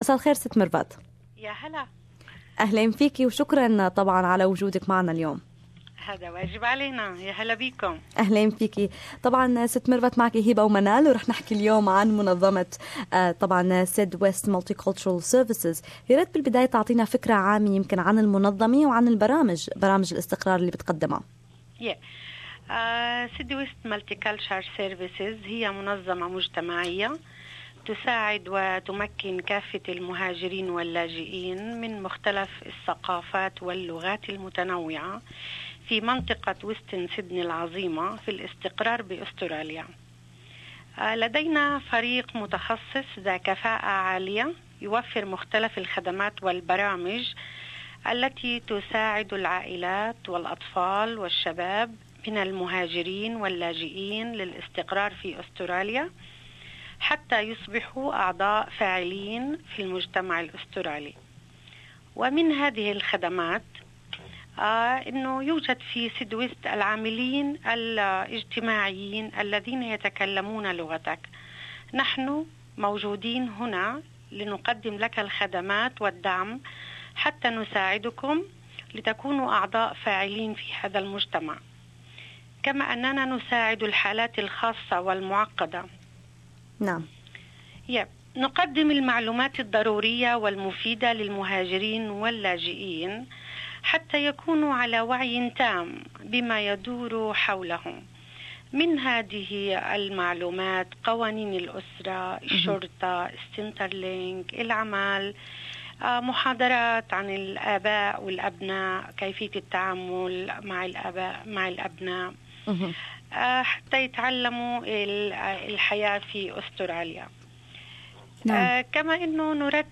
تقدم منظمة الخدمات متعددة الثقافات في منطقة غرب سيدني SydWest Multicultural Services العديد من الخدمات للاجئين والقادمين الجدد إلى أستراليا.. المزيد عن هذا الموضوع في المقابلة المباشرة ادناه